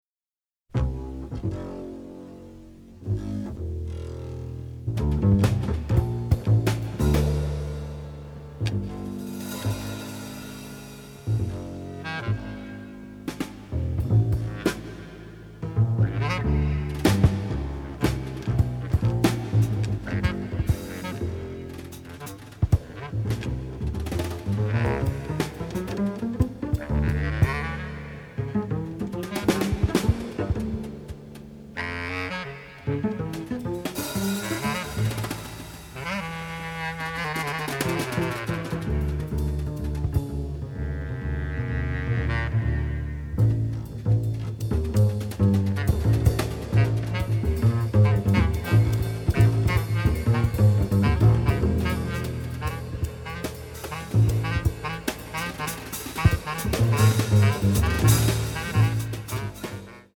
romantic, innovative, masterful score
cool jazz cues